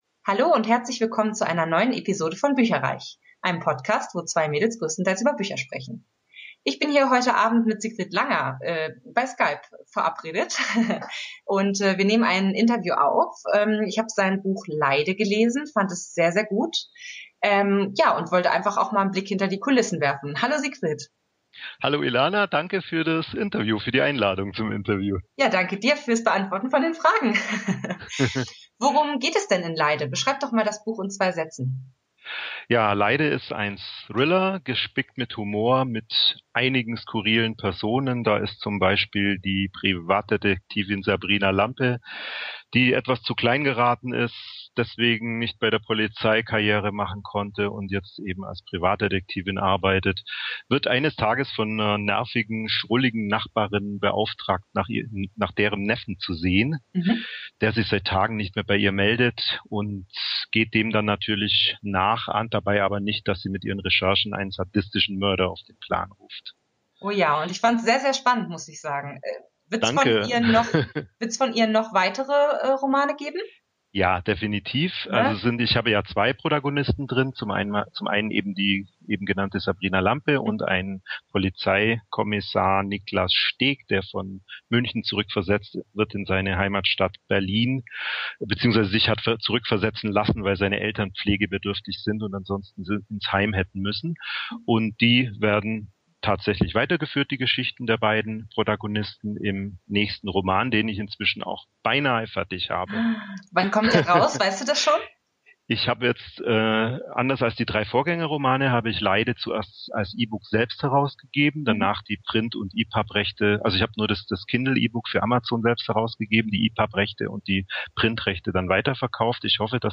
Beschreibung vor 11 Jahren Willkommen bei bücherreich, unserem Bücher-Podcast!
Entschuldigt bitte, dass die Tonqualität leider nicht auf unserem üblichen Niveau ist, da das Interview via Skype geführt und aufgenommen wurde.